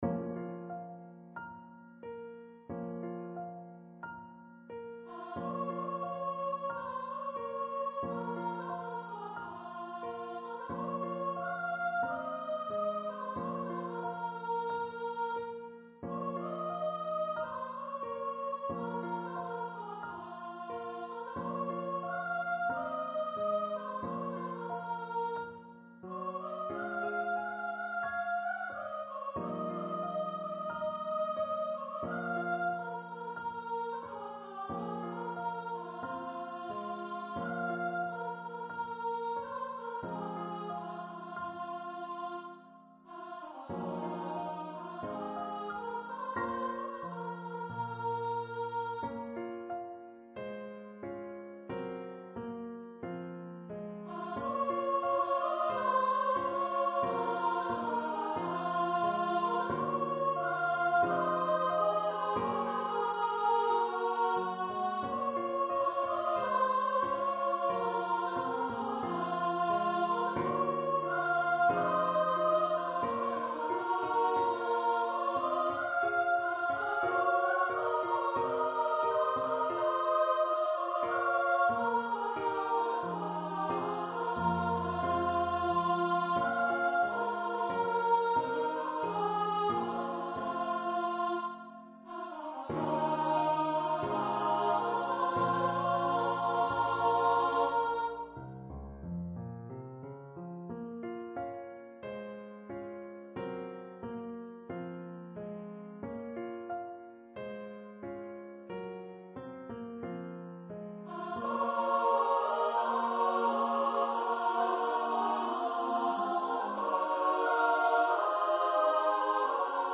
arranged for upper voice choir
Choir - 3 part upper voices